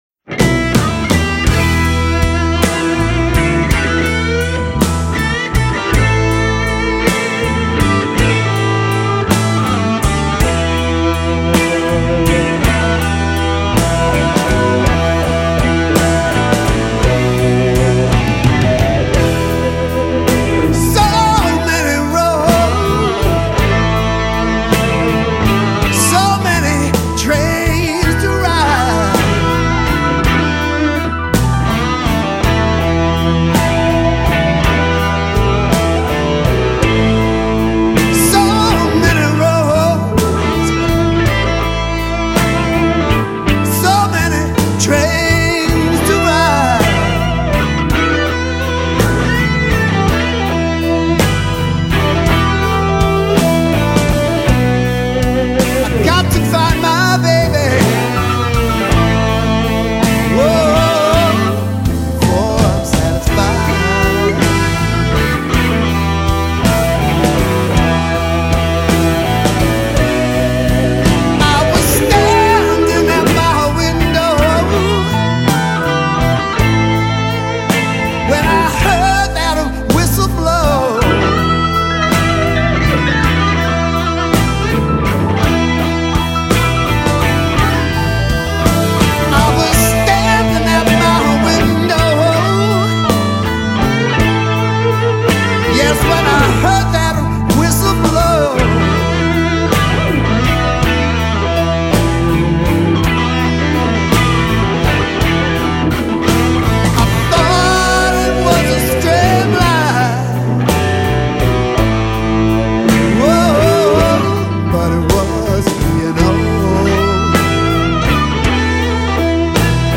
These dudes can still rock-it...